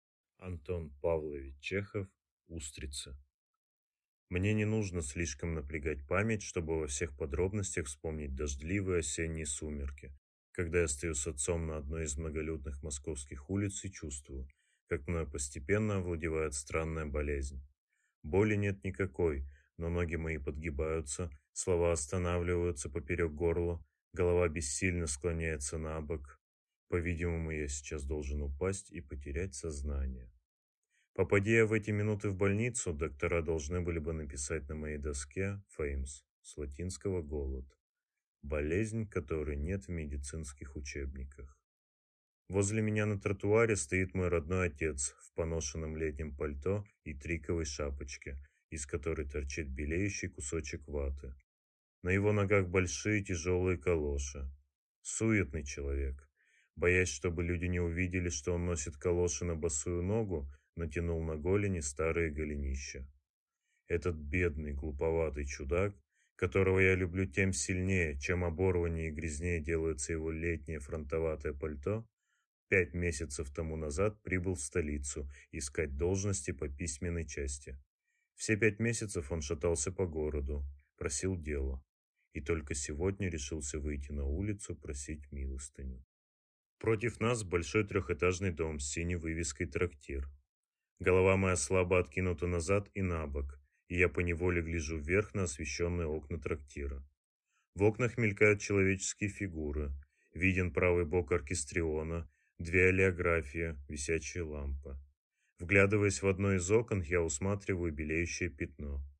Аудиокнига Устрицы | Библиотека аудиокниг
Прослушать и бесплатно скачать фрагмент аудиокниги